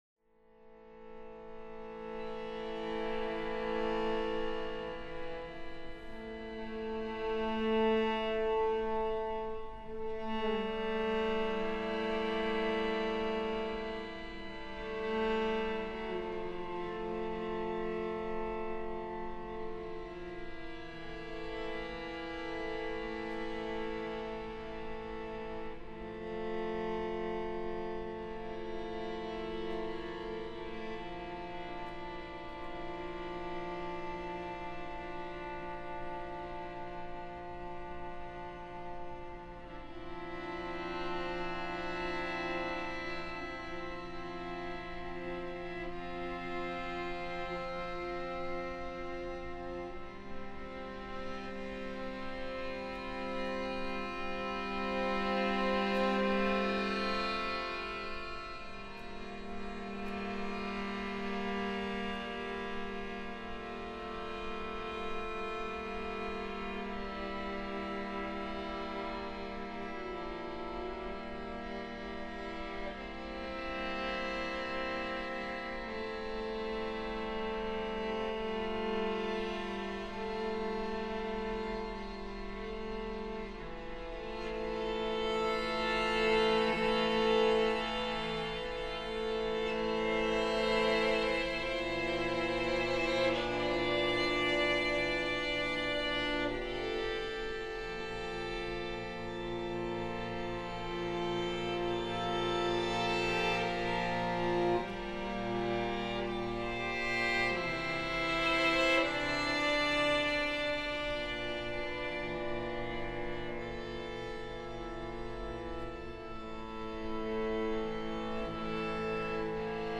Slow Improv
Cello